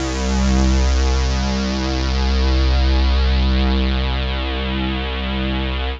Index of /90_sSampleCDs/Trance_Explosion_Vol1/Instrument Multi-samples/Angry Trance Pad
C3_angry_trance_pad.wav